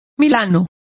Complete with pronunciation of the translation of kite.